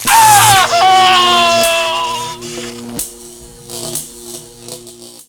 electrocute.ogg